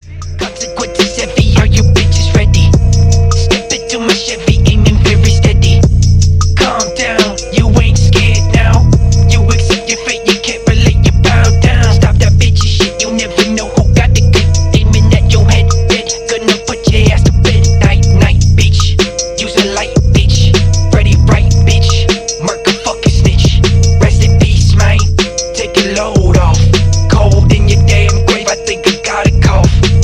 • Качество: 320, Stereo
громкие
Hiphop
Gangsta